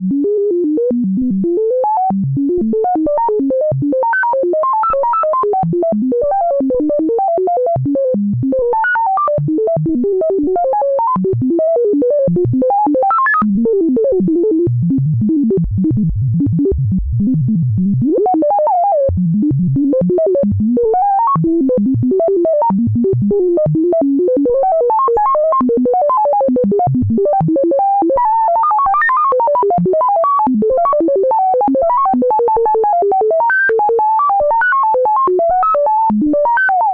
穆格电子声音
描述：使用Moog Rogue制作的电子声音。
Tag: 模拟 电子 arpgeggiator 合成器 流氓 合成器 随机 穆格